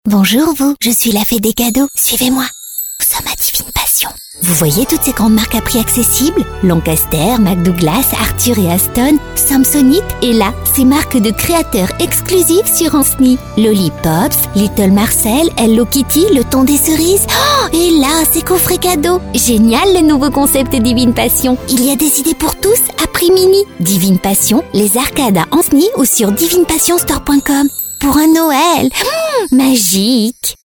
Comédienne voix-off professionnelle tout type de voix et de projet.
Sprechprobe: Werbung (Muttersprache):
Professional voiceover actress all types of voice and project.